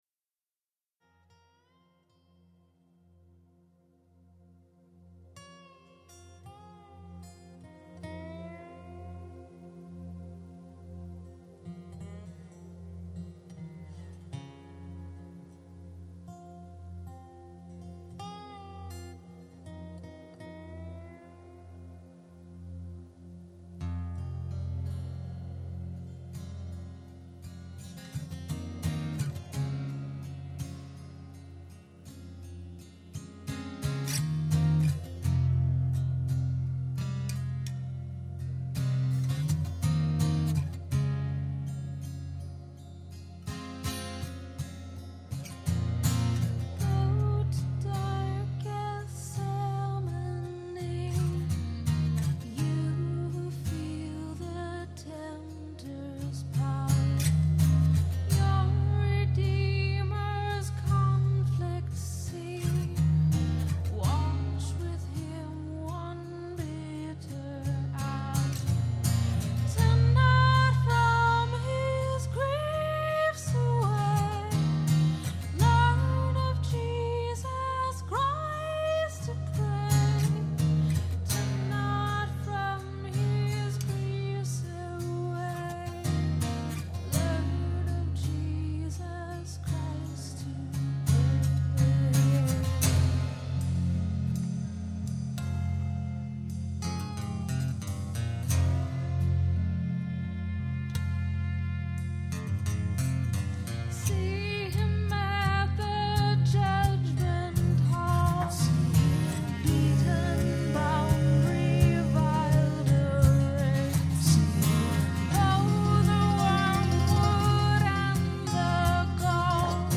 Performed live on Good Friday at Terra Nova - Troy on 3/21/08.